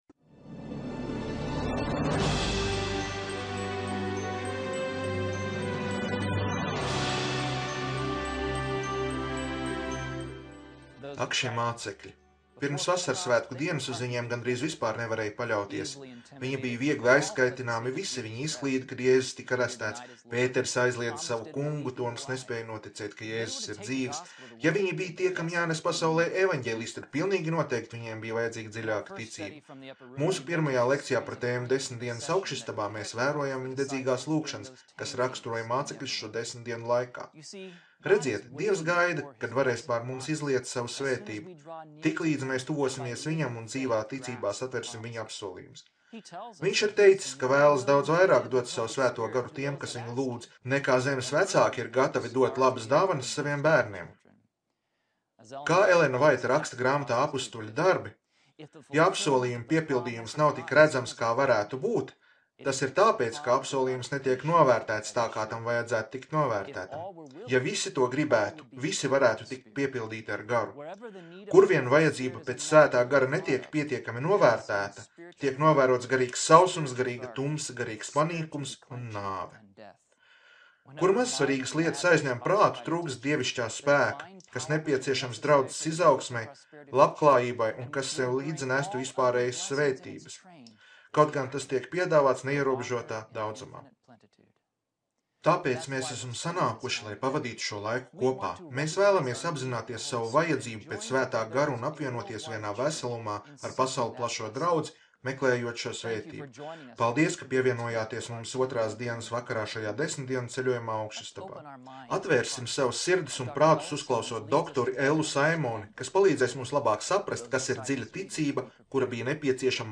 Seminārs - 10 dienas augšistabā